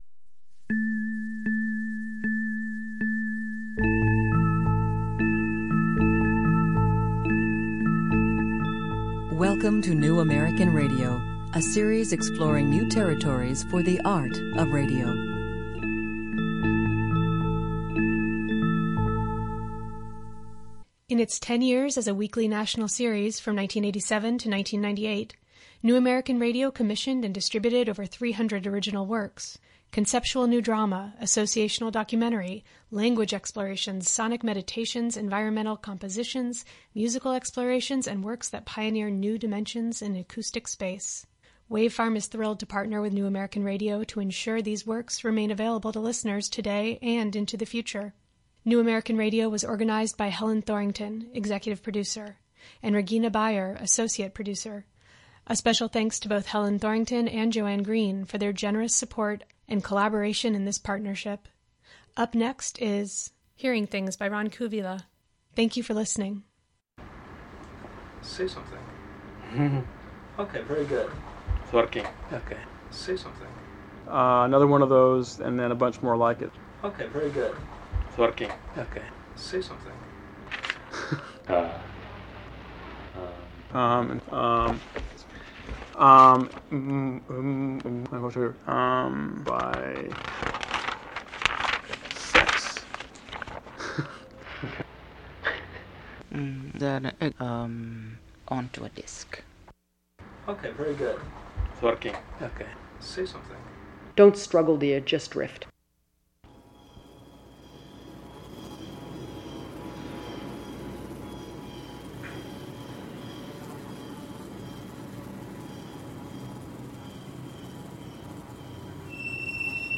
Uses music, interviews, and other sonic specimens...
Uses music, interviews, and other sonic specimens in a meditation on the unheard and the unhearable. An example of unheard sound is found in the 5 Hertz chirp produced at irregular intervals by the Tappan Zee Bridge. More figuratively, there is the Foley artist, who carefully crafts "misheard" sound effects.